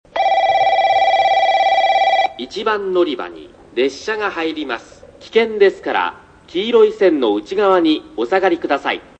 スピーカー：UNI-PEX・SC-10JA（ソノコラム）
音質：C
スピーカーの位置が高く、海から抜ける風が強い日は収録には不向きでした。
１番のりば 接近放送・男性 （上り・小倉方面） (45KB/09秒)